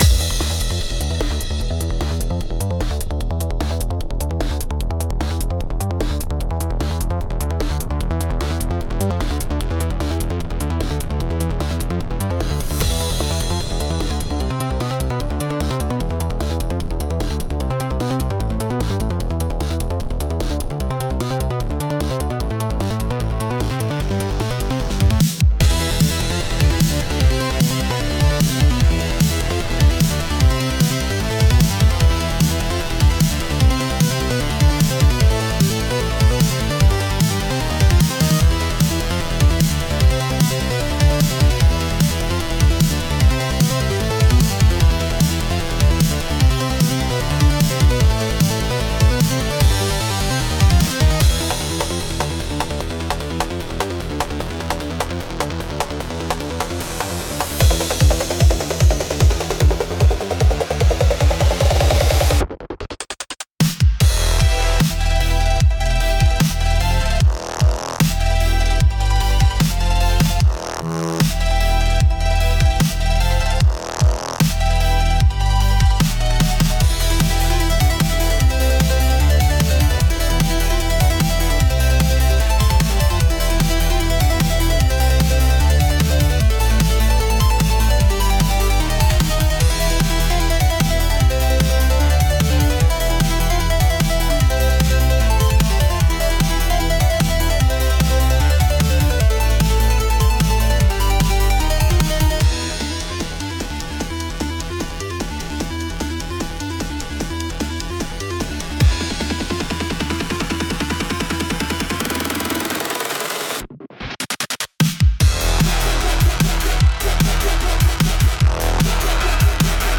公開している音楽は、映画やアニメ、ゲームに想定したBGMや、作業用のBGMを意識して制作しています。